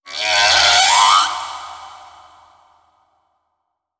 khloCritter_Female19-Verb.wav